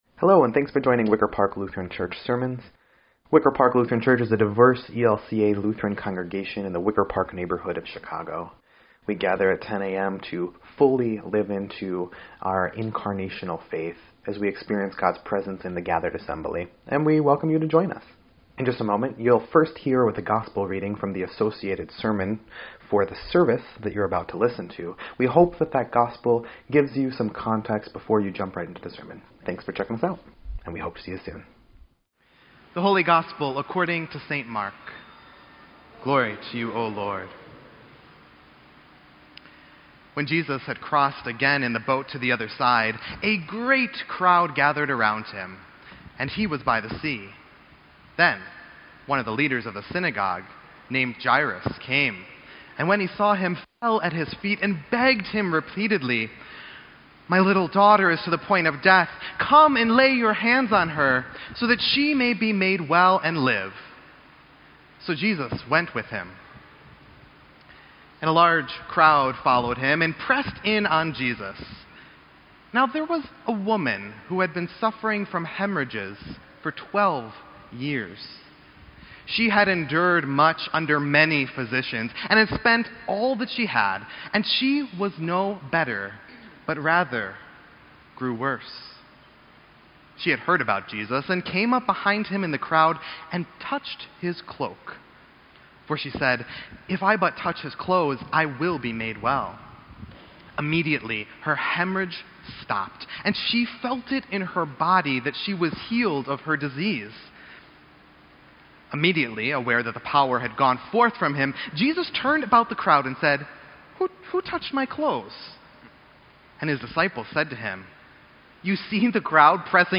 Sermon_7_1_18_EDIT.mp3